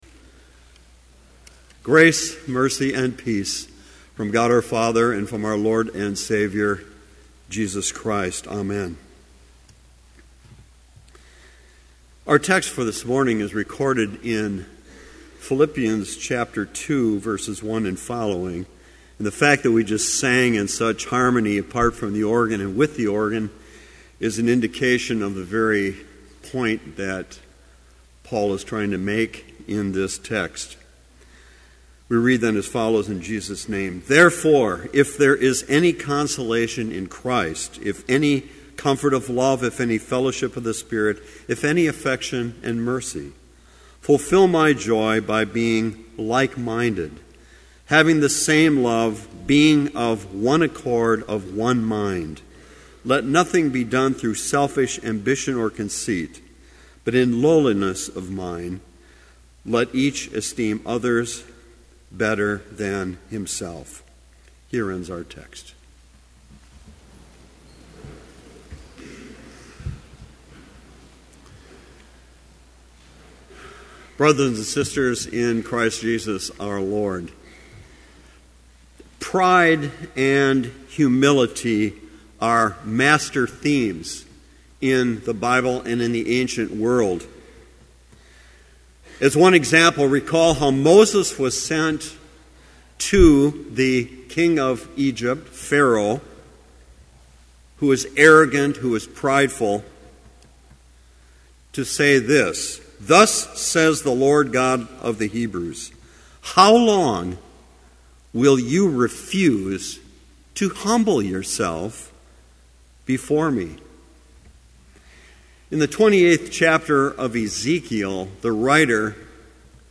Complete service audio for Chapel - September 9, 2011